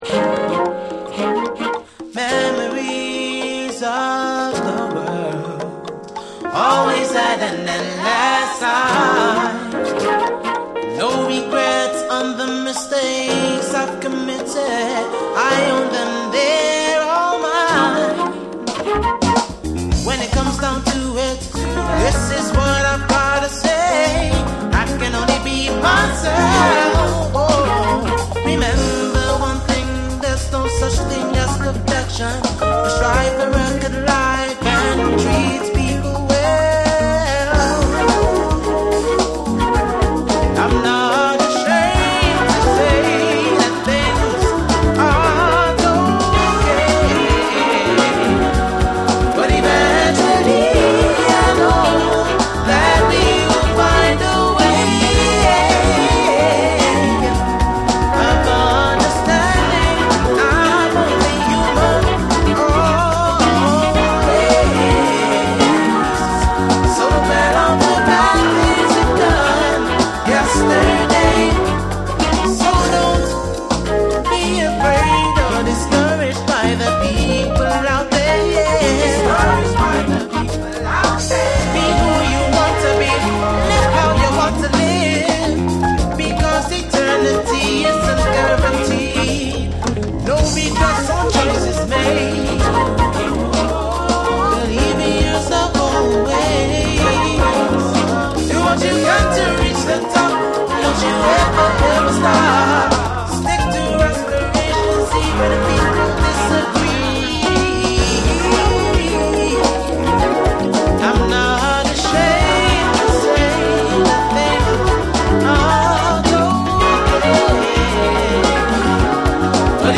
BREAKBEATS / SOUL & FUNK & JAZZ & etc